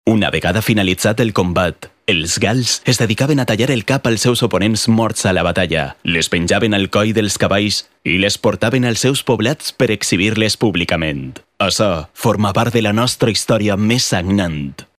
Valencian voice talent